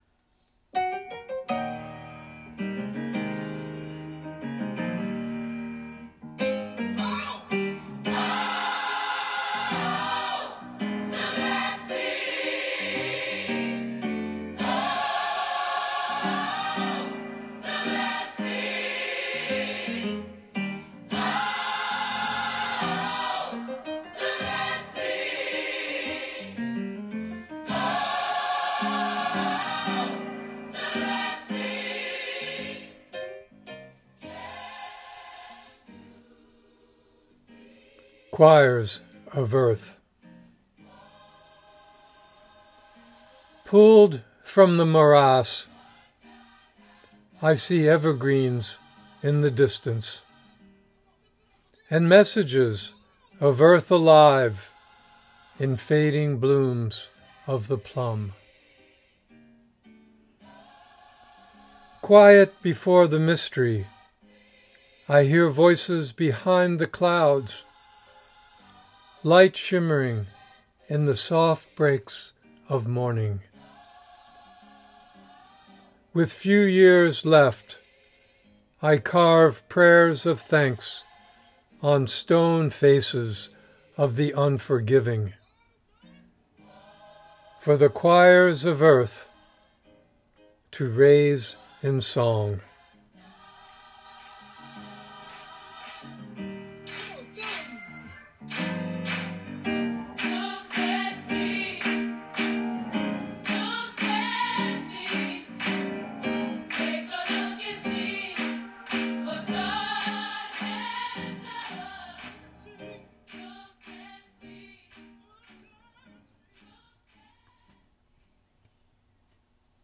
Reading of “Choirs of Earth” with music by the Sunday Service Choir.